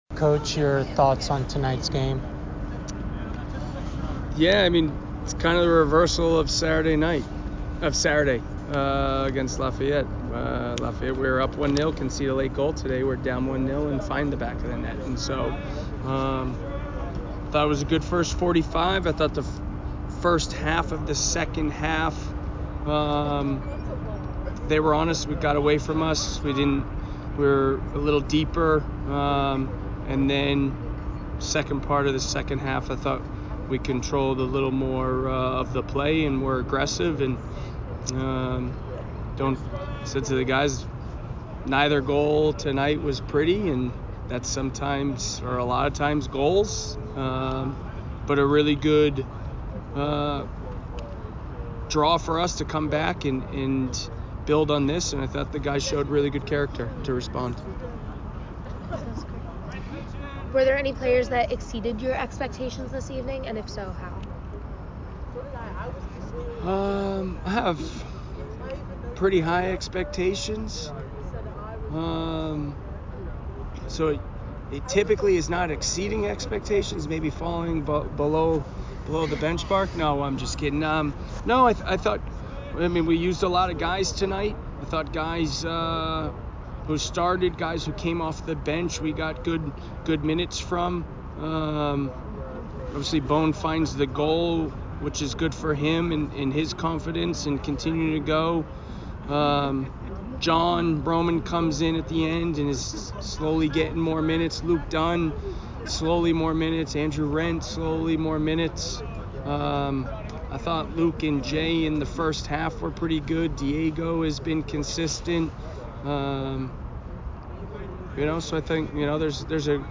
Harvard Postgame Interview